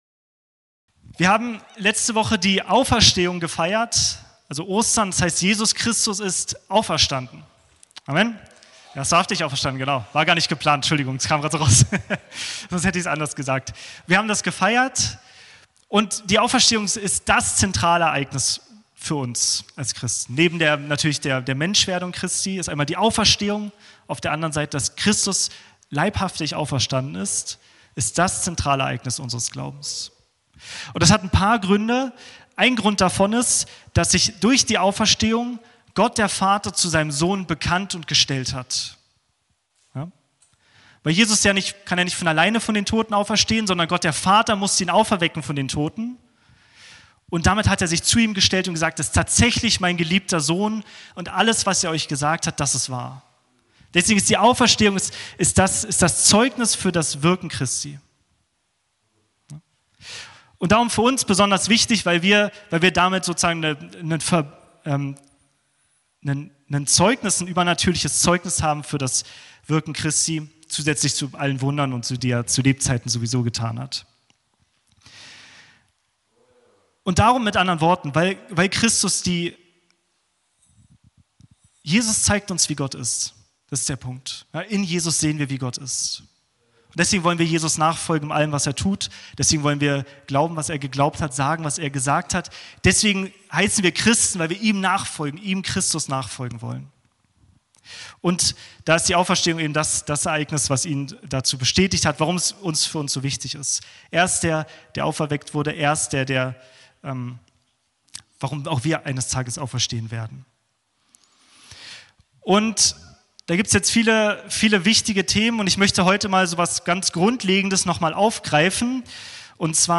Gottesdienst vom 12.04.2026